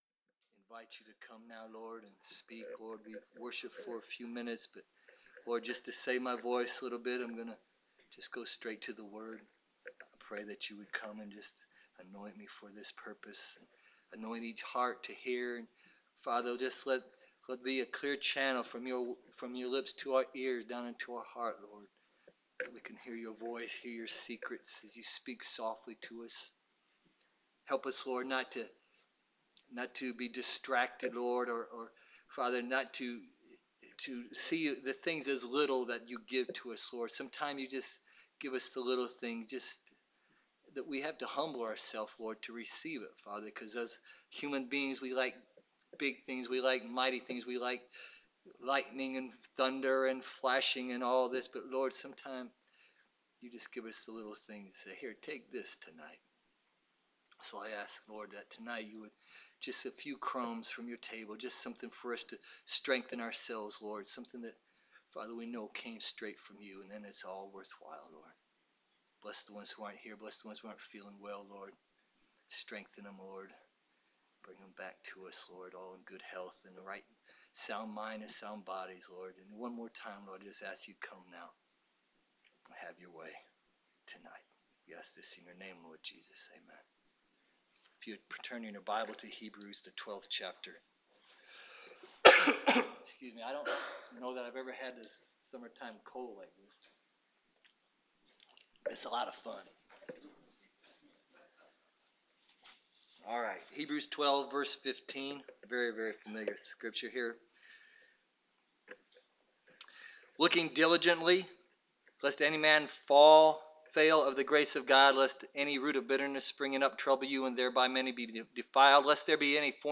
Preached 09 July 2015